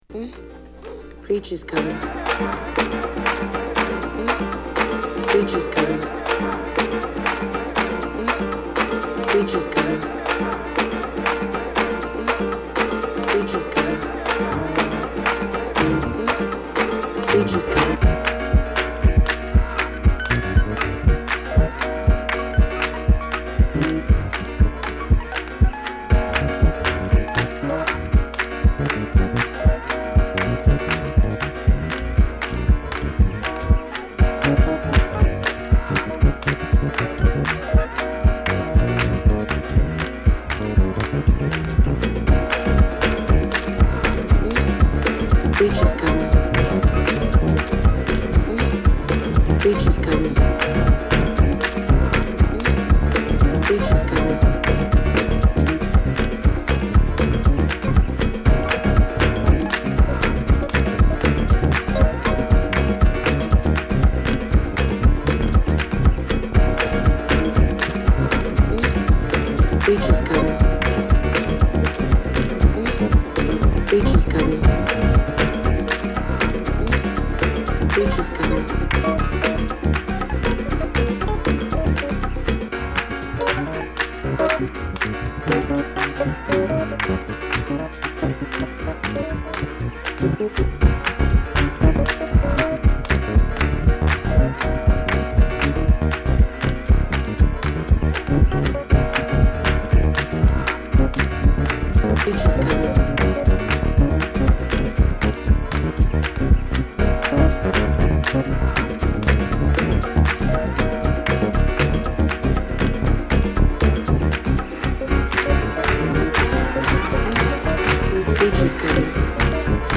ハンドクラップにエレピ／オルガン、蠢くベースが一体となったビート／ハウス・グルーヴに